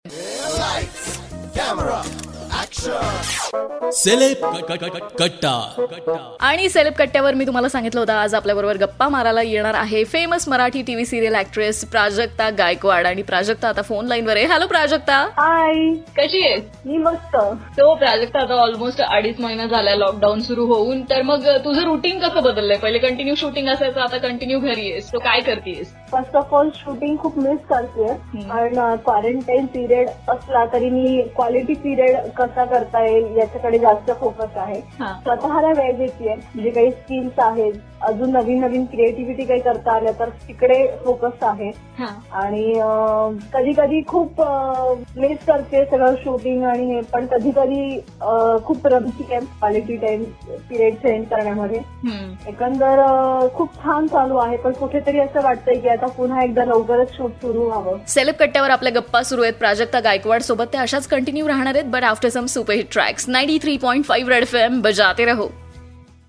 In this interview she shared her lockdown routine..